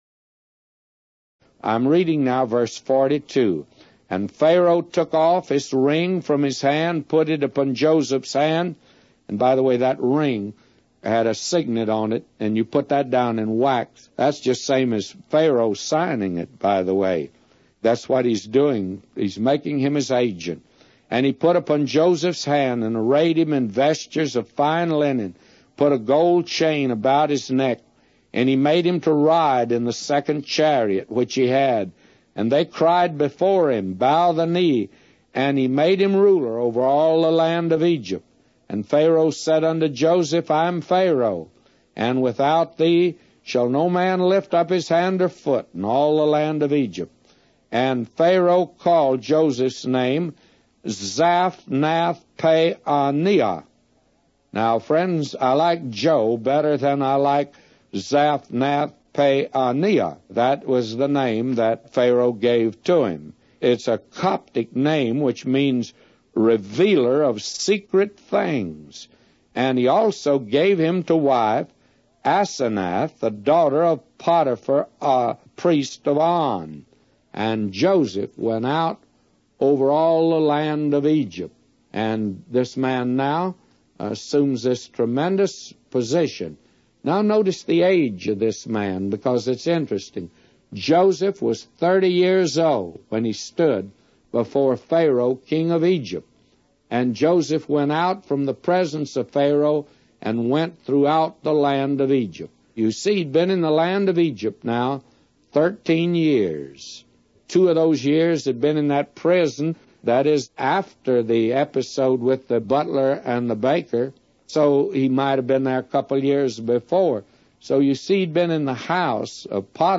A Commentary By J Vernon MCgee For Genesis 41:42-999